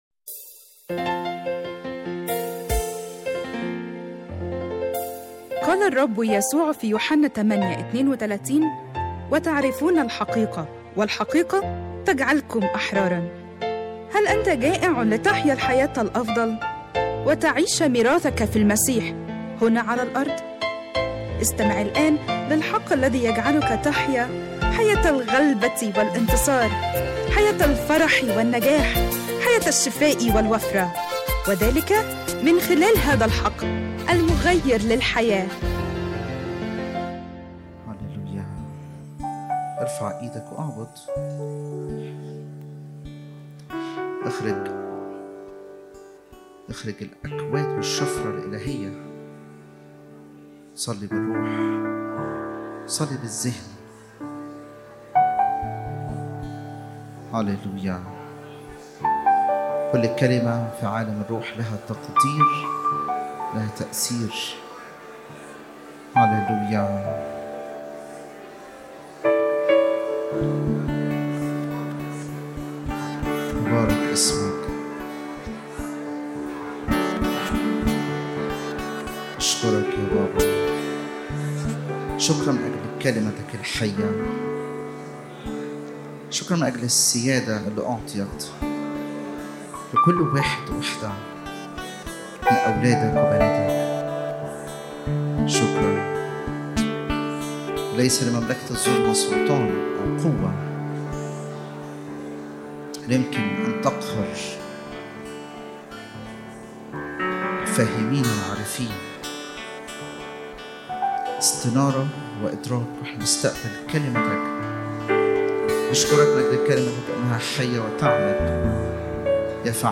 🎧 Download Audio 1 تحميل اجتماع الثلاثاء 24/2/2026 لسماع العظة على الساوند كلاود أضغط هنا لمشاهدة العظة على اليوتيوب من تأليف وإعداد وجمع خدمة الحق المغير للحياة وجميع الحقوق محفوظة.